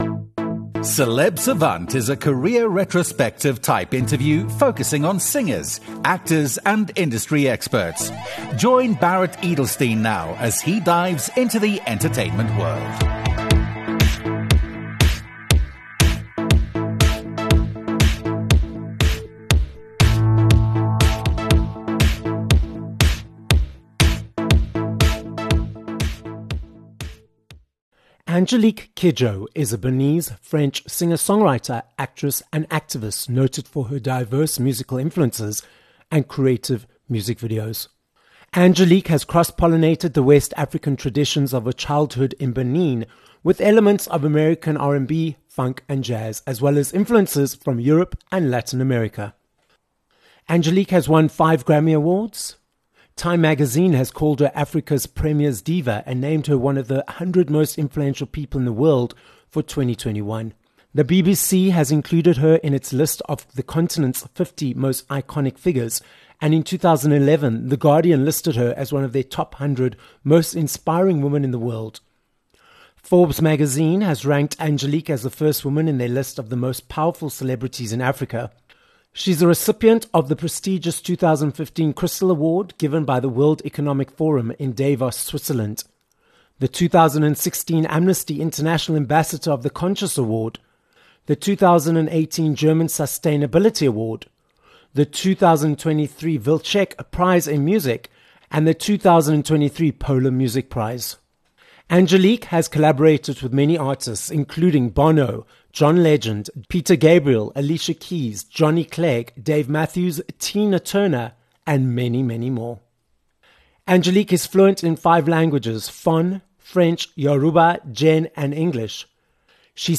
3 Sep Angélique Kidjo, 5 time Grammy winner speaks out - interview